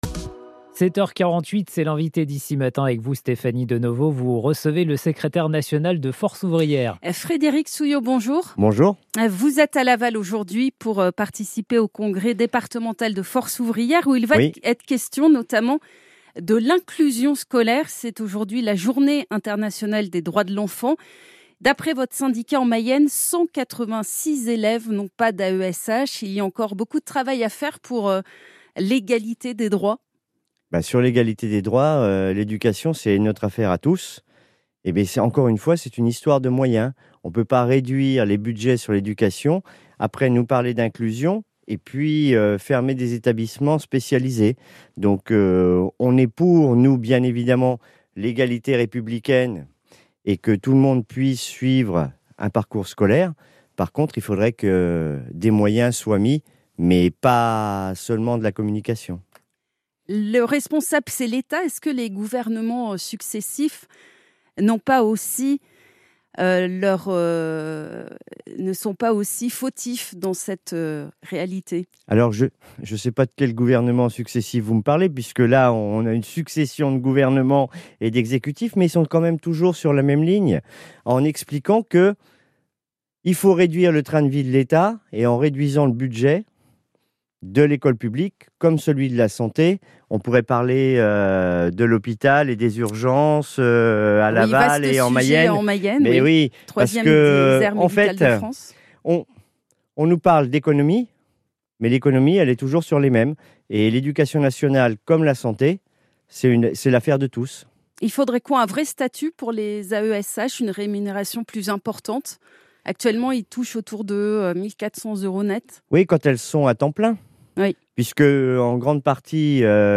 Frédéric Souillot, Secrétaire général de Force Ouvrière, était l’invité d’ICI Mayenne le 20 novembre 2025.